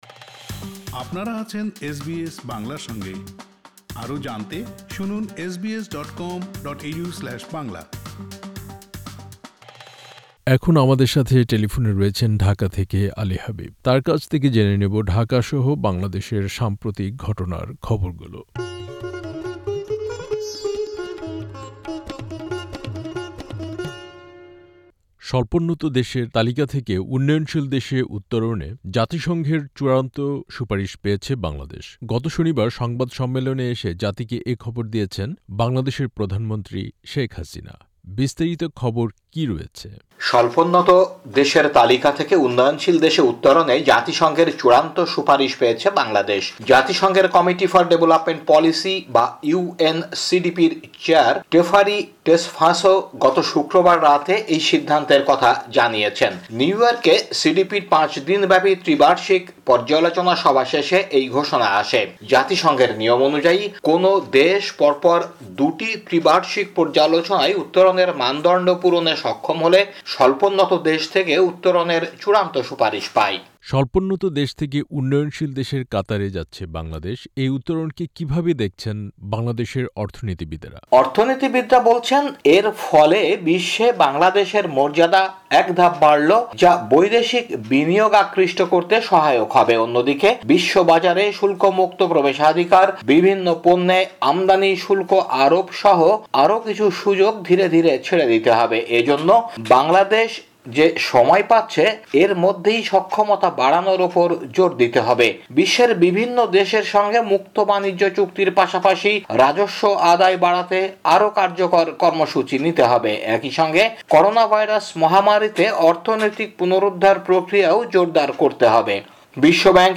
বাংলাদেশের সাম্প্রতিক ঘটনার খবর নিয়ে আমাদের সাথে টেলিফোনে আছেন